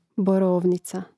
boróvnica borovnica